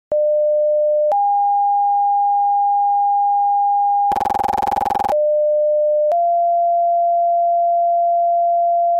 pager-tone_25015.mp3